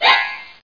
1 channel
00684_Sound_feathers.mp3